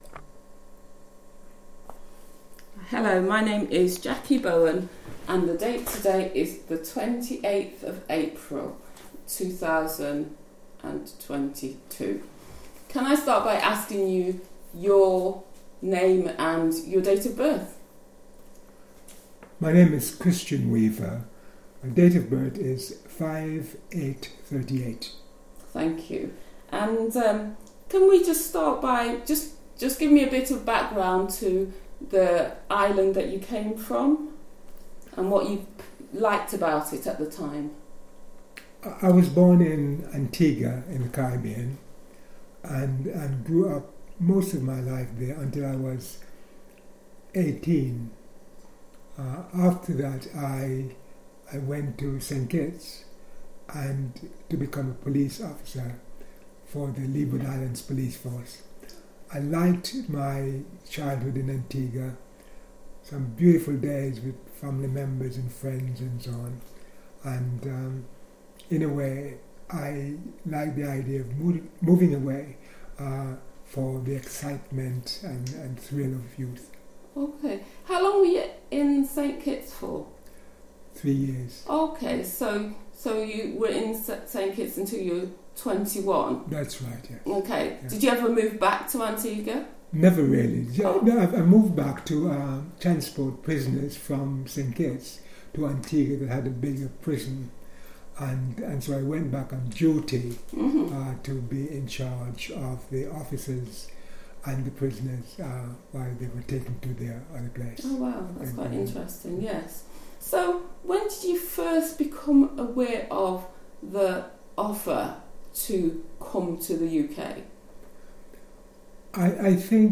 As part of a National Lottery Heritage fund project, local Nottingham charity Always Community interviewed over 50 members of the Windrush generation. This exciting project asked a simple question – Why did the interviewee make their Windrush Decision?